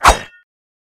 刀割.mp3